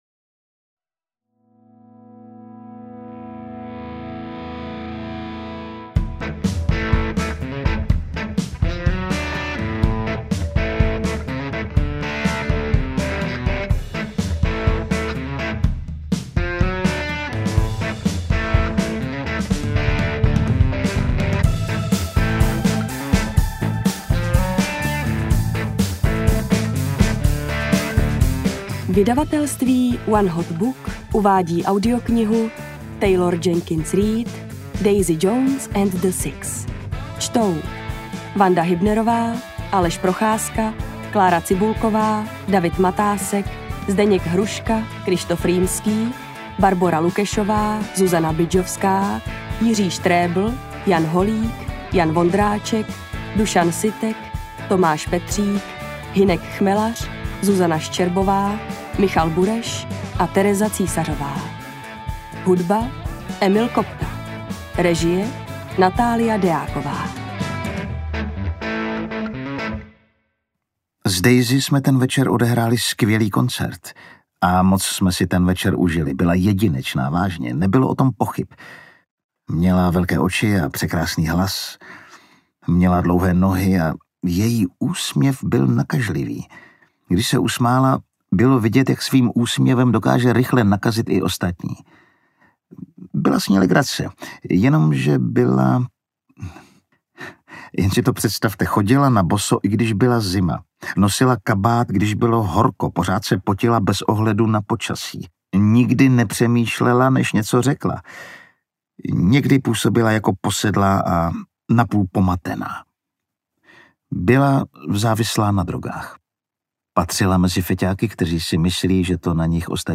Daisy Jones & The Six audiokniha
Ukázka z knihy